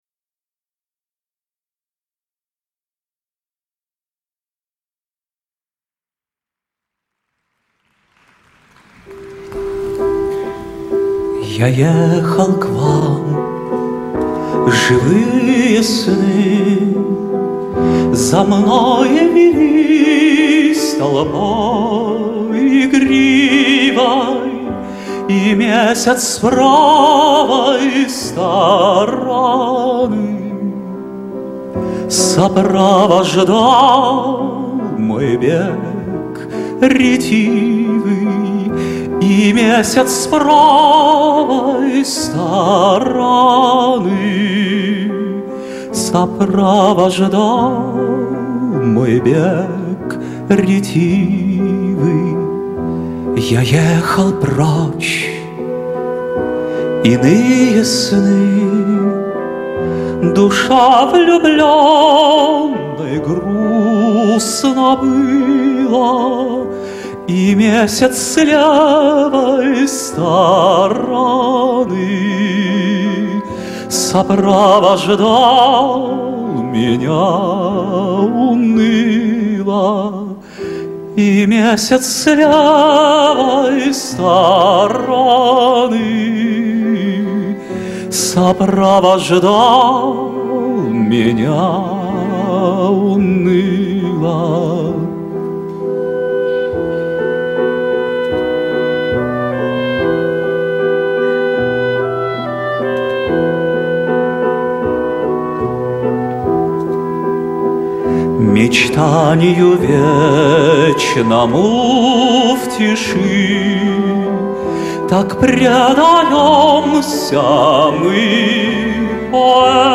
Аудиофайл только в виде рипа с видео....